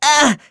Neraxis-Vox_Damage_01.wav